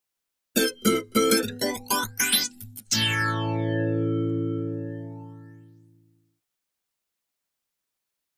Acoustic Guitar - Happy Rhythm - Flange